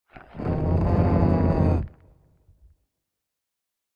Minecraft Version Minecraft Version latest Latest Release | Latest Snapshot latest / assets / minecraft / sounds / mob / warden / ambient_10.ogg Compare With Compare With Latest Release | Latest Snapshot
ambient_10.ogg